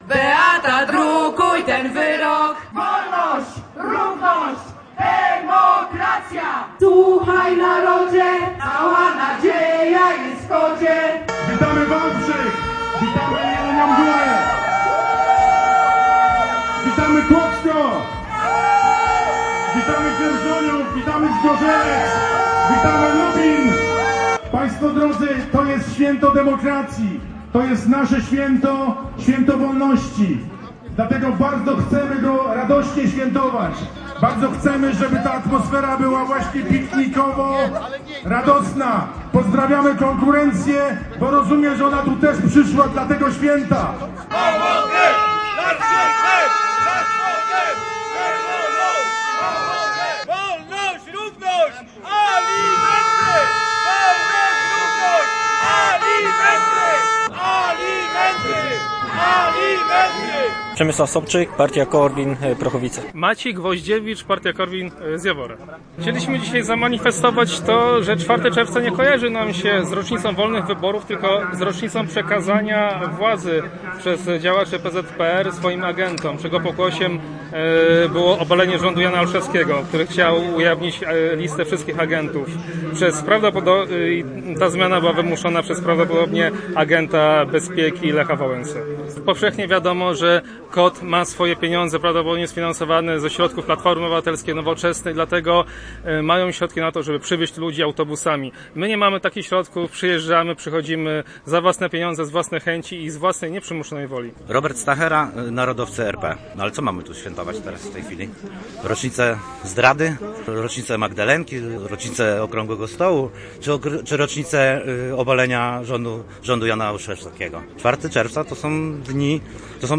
Manifestacja KOD-u w Legnicy: